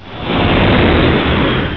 Airplane.wav